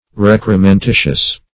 Search Result for " recrementitious" : The Collaborative International Dictionary of English v.0.48: Recrementitious \Rec`re*men*ti"tious\ (-t?sh"?s), a. Of or pertaining to recrement; consisting of recrement or dross.